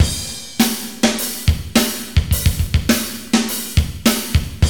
ROCK BEAT 1.wav